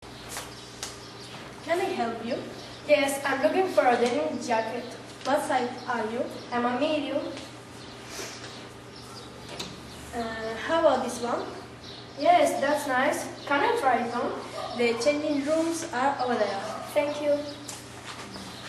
Una chica mira ropa en una tienda, la dependienta se le acerca y mantienen una conversación